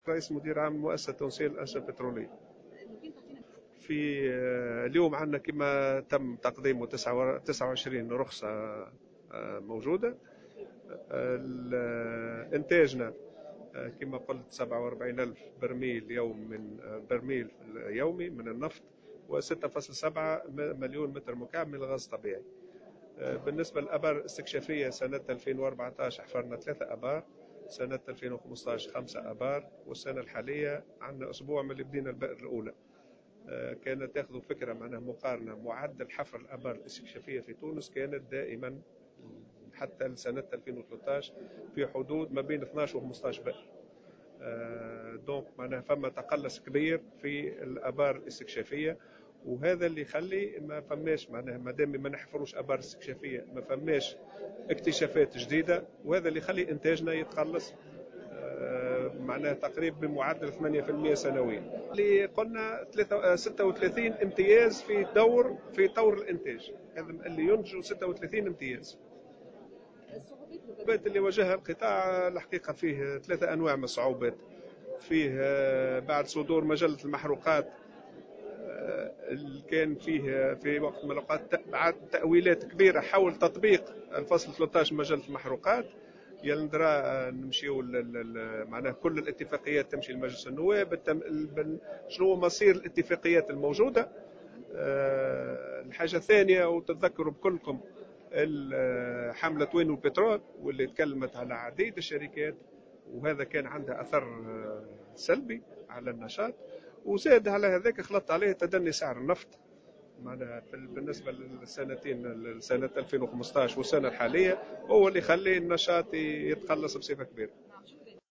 في تصريحات صحفية على هامش ندوة صحفية